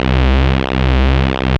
SYNTH BASS-2 0004.wav